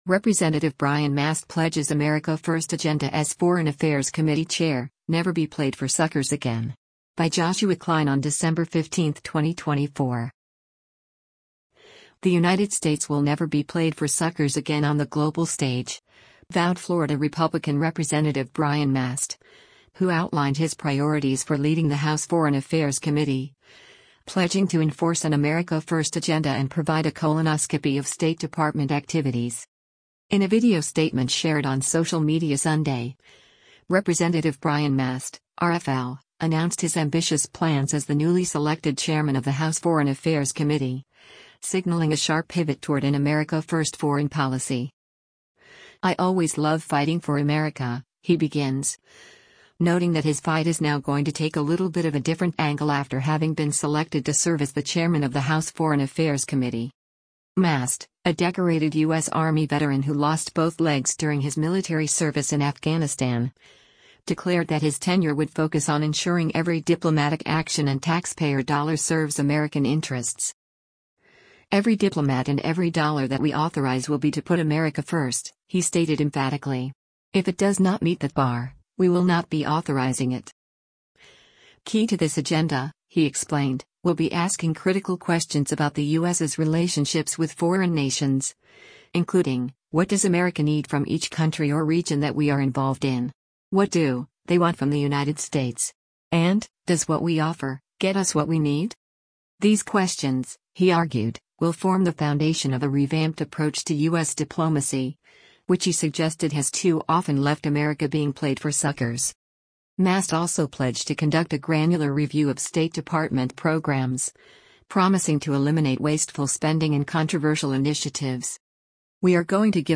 In a video statement shared on social media Sunday, Rep. Brian Mast (R-FL) announced his ambitious plans as the newly selected chairman of the House Foreign Affairs Committee, signaling a sharp pivot toward an “America First” foreign policy.